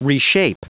Prononciation du mot reshape en anglais (fichier audio)
Prononciation du mot : reshape